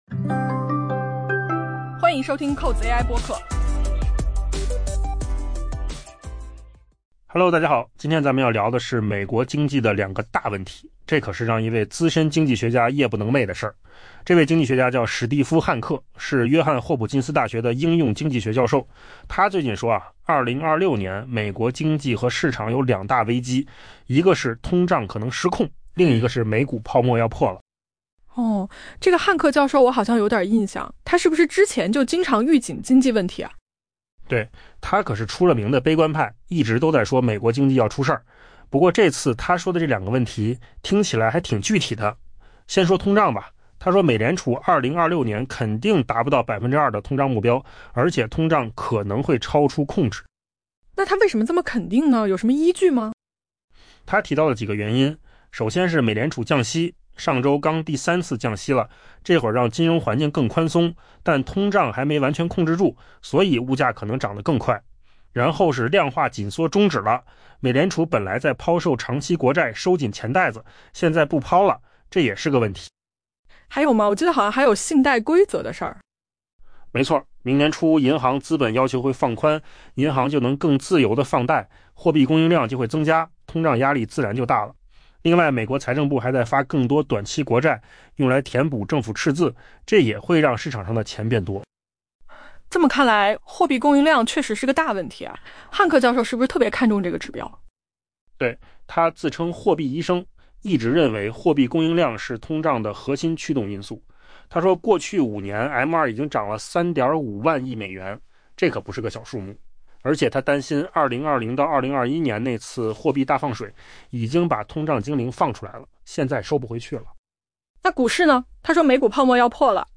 AI 播客：换个方式听新闻 下载 mp3 音频由扣子空间生成 当华尔街准备在乐观情绪中迎接 2026 年时，资深经济学家、约翰霍普金斯大学应用经济学教授史蒂夫·汉克 （Steve Hanke） 表示，美国经济和市场存在两大重大问题，让他难以安睡。